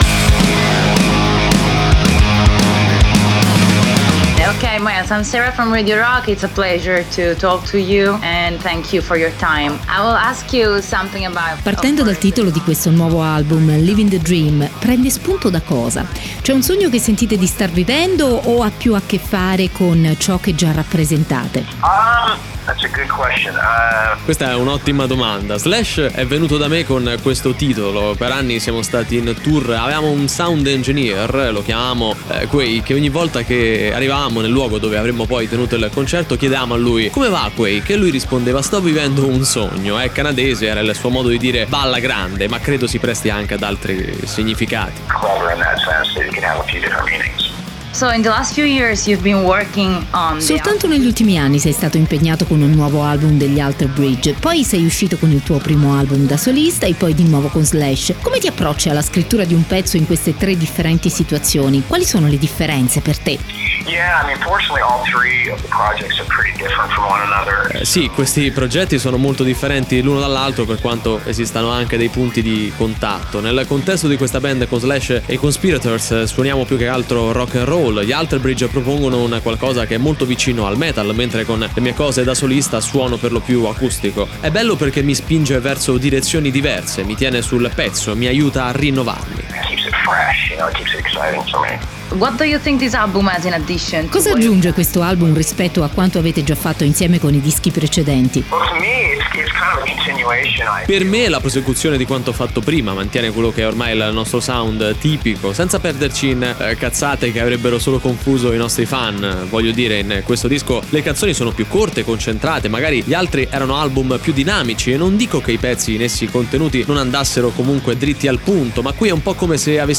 Intervista: Myles Kennedy (21-09-18)